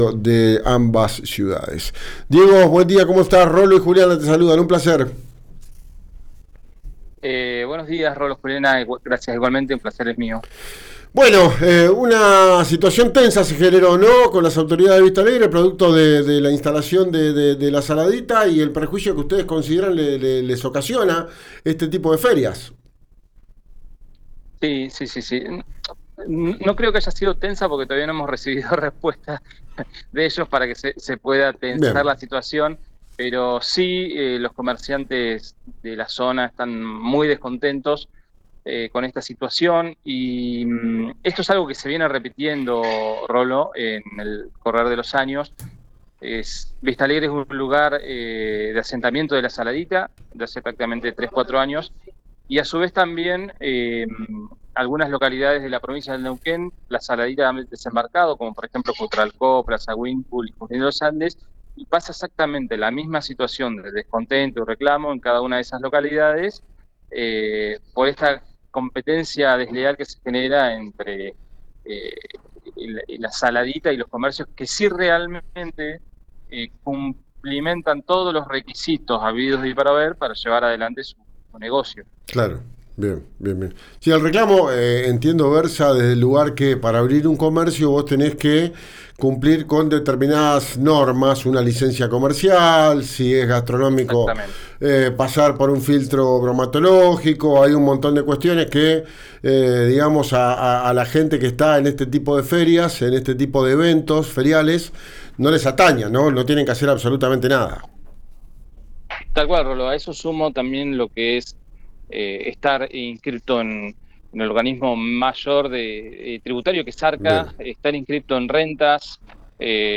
en el aire de RÍO NEGRO RADIO